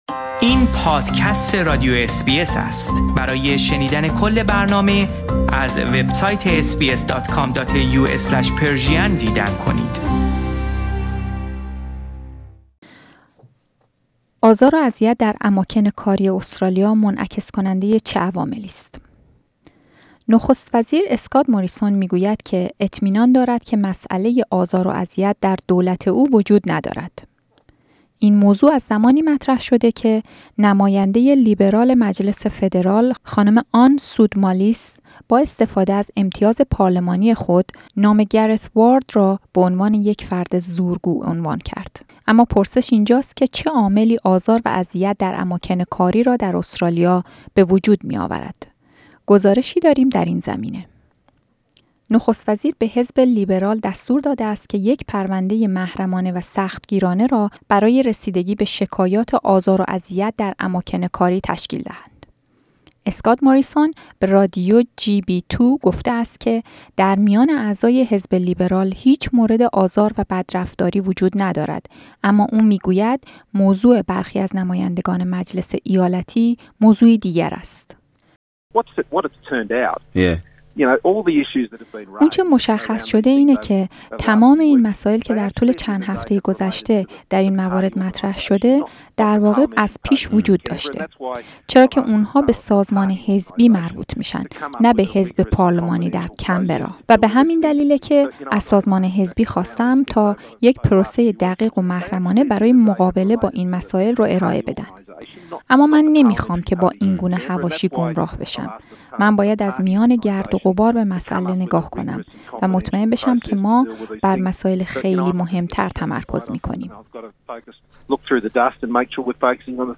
اما پرسش اینجاست که چه عاملی آزار و اذیت در اماکن کاری را در استرالیا به وجود می آورد؟ گزارشی داریم در این زمینه.